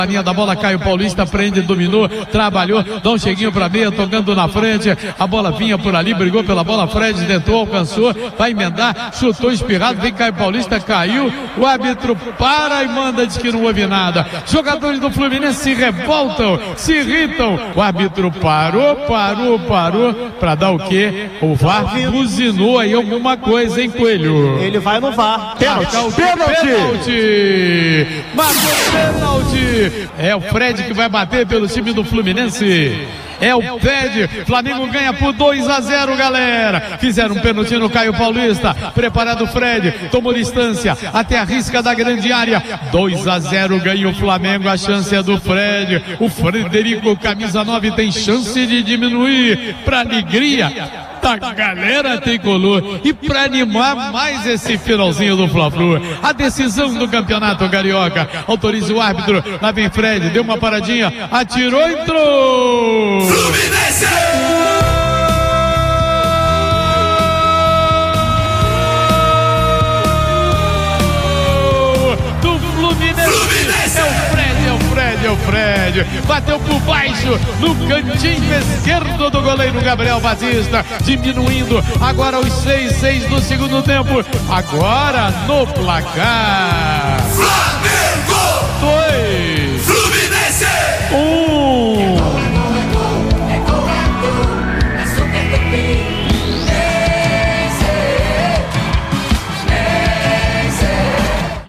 Ouça os gols da vitória e conquista do Campeonato Carioca do Flamengo sobre o Fluminense com a narração do Garotinho